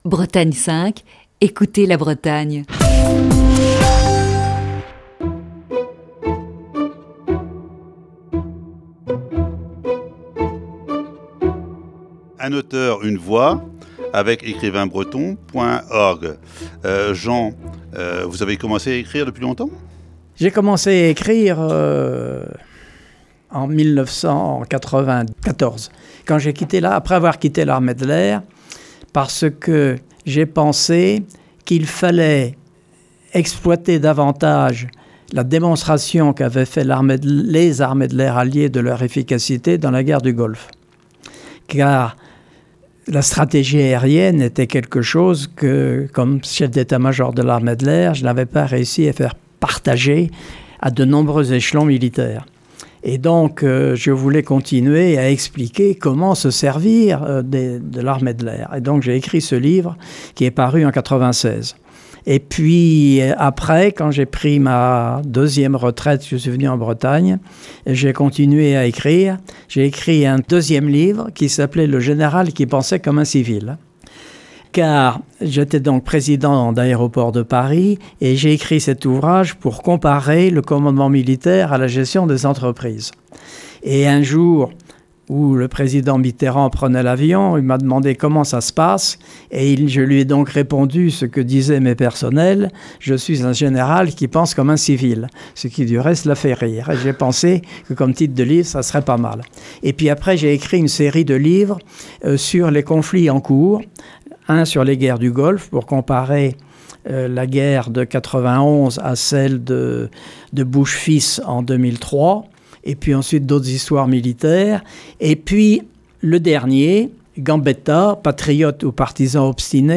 Voici ce mercredi, la troisième partie de cette série d'entretiens.